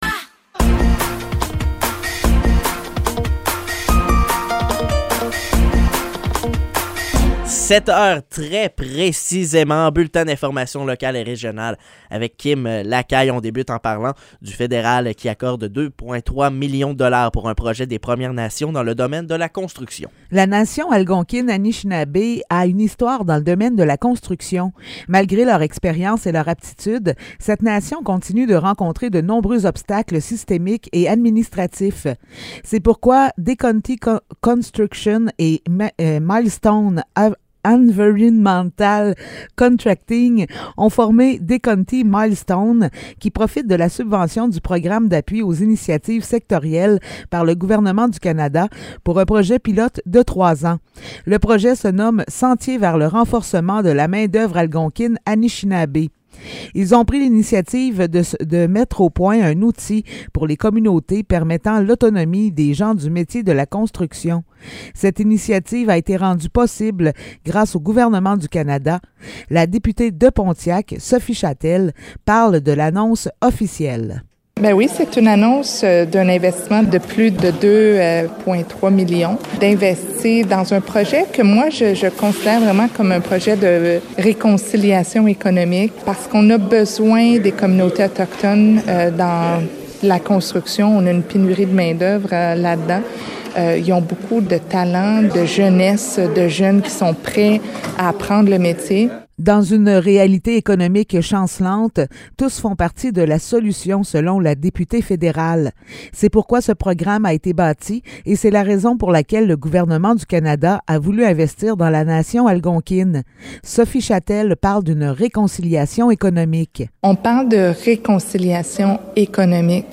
Nouvelles locales - 22 février 2023 - 7 h